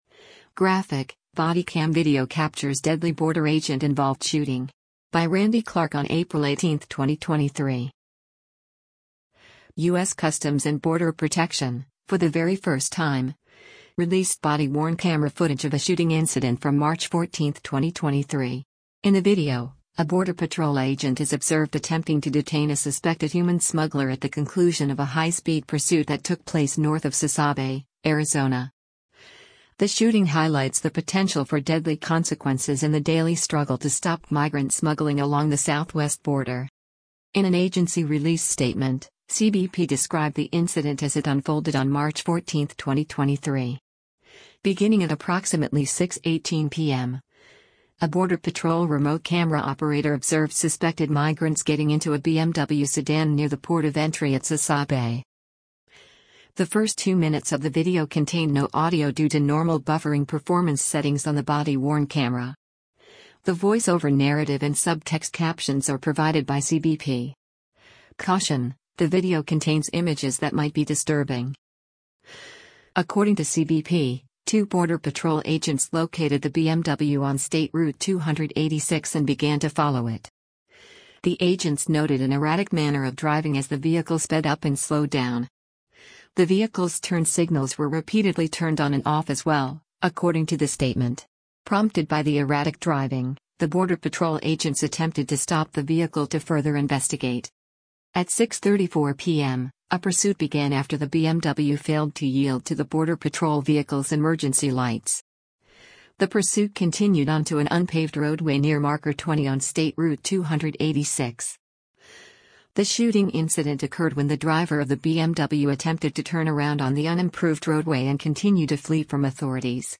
The first two minutes of the video contained no audio due to normal buffering performance settings on the body-worn camera. The voice-over narrative and subtext captions are provided by CBP.
The occupant shouted to the agent, “I didn’t want him backing up into me, sir”.